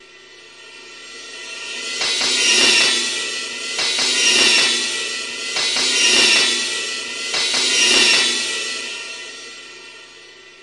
火车声
描述：训练声音，在计算机上创建。
Tag: 合成器 TECHNO 火车 噪声